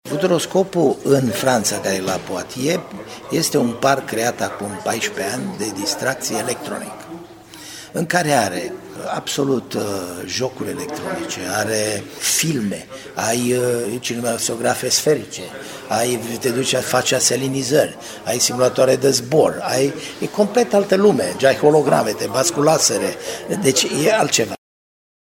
Timișoara ar putea avea parc de distracții cu jocuri electronice, pe o suprafață de 20 de hectare, după model francez. Este vorba despre Futuroscop, care pe lângă divertisment, va oferi posibilitatea cercetării și dezvoltării de noi tehnologii în domeniul IT, spune consulul onorific al Franței la Timișoara, Dan Bedros.